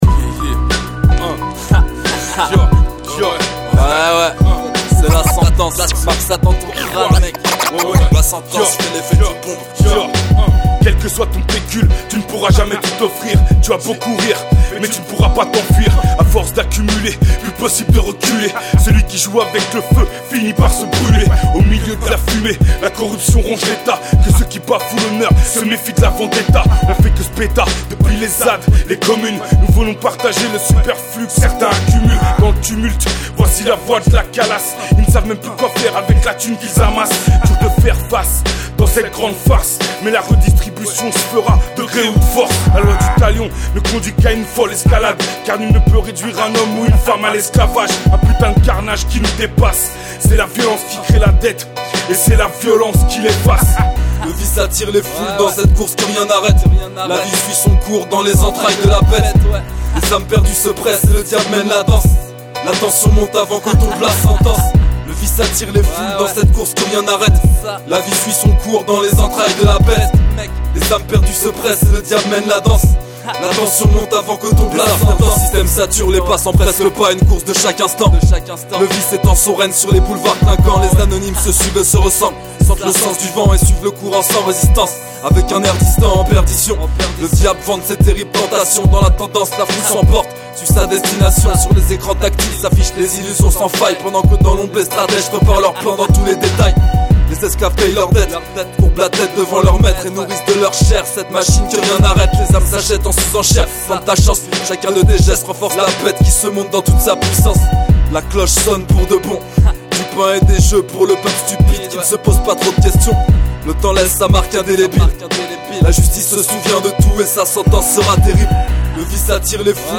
scratch